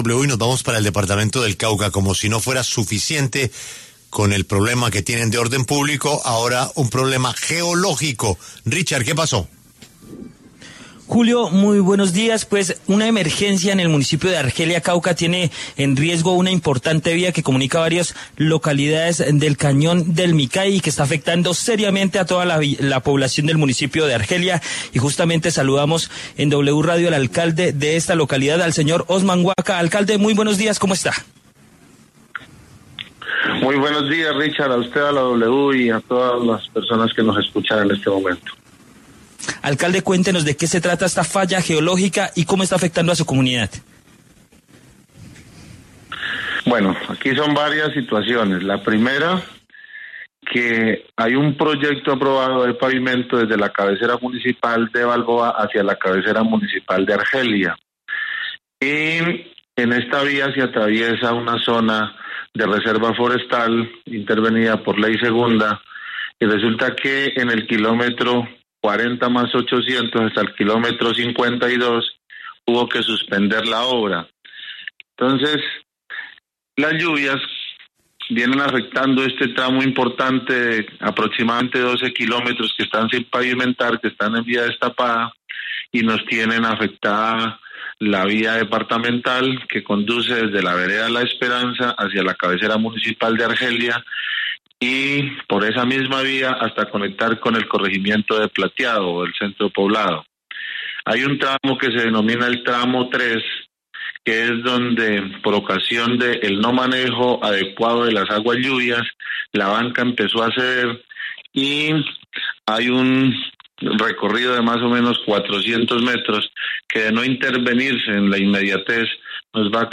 Osman Guaca, alcalde de Argelia, informó en entrevista con la W que las lluvias han afectado severamente un tramo de aproximadamente 12 kilómetros que aún no está pavimentado, lo que complica la movilidad de la población local y afecta el transporte de bienes y servicios esenciales.